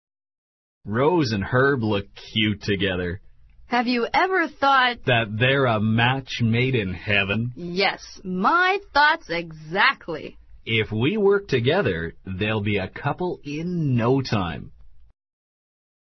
网络社交口语对话第59集： 他们是天造地设的一对
SCENE② B 欢送会上 苏和唐看着柔丝和赫伯